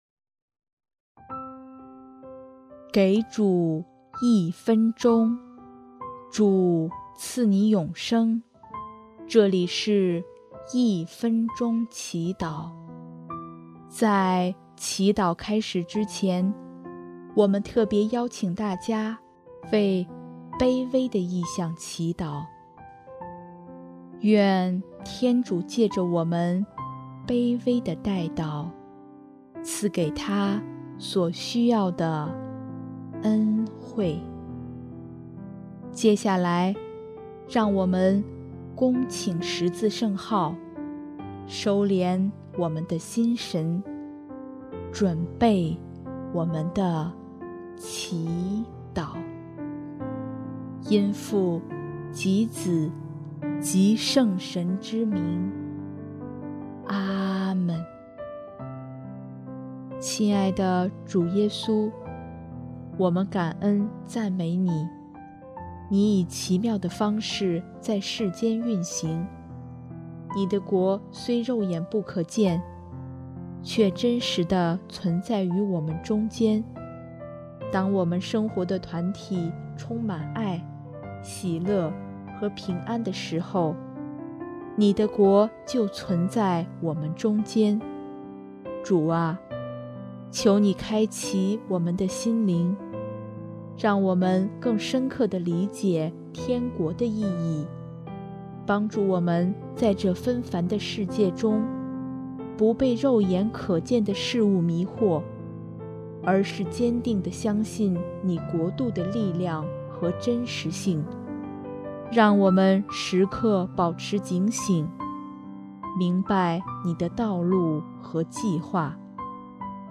【一分钟祈祷】|11月14日 天国的来临